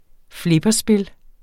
Udtale [ ˈflebʌ- ]